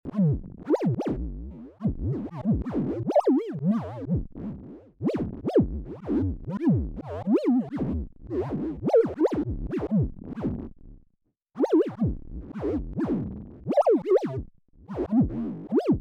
In the meantime, you can make some pretty good conversations between birds in a space rainforest with just a Minibrute :upside_down_face: